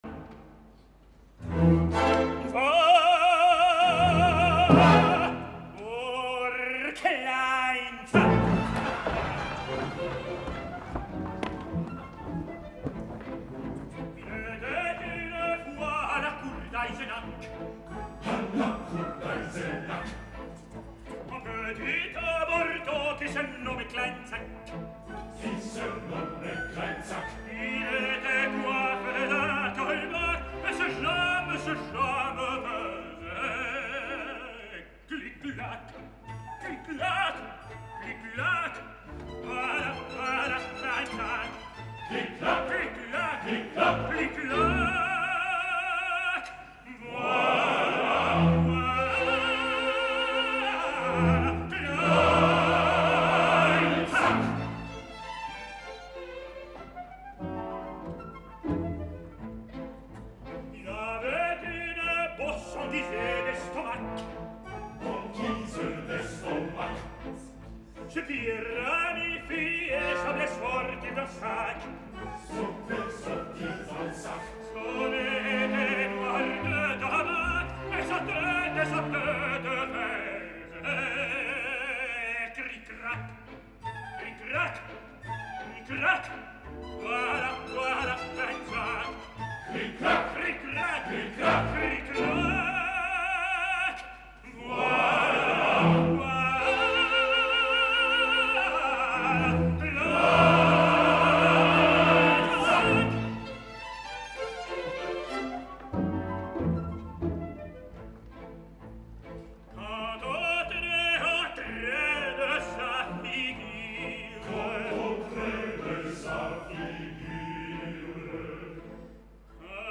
Sense fiato i amb un volum molt escàs, malgrat tenir els micròfons al davant, quedava constantment tapat per Damrau, i sobretot una sensació de por, de poca confiança
Escoltem en primer lloc la llegenda de Kleinzach que canta Hoffmann al pròleg i on ja apreciareu els primers símptomes de defallença i manca de  seguretat vocal del tenor, superats a base de passió desbocada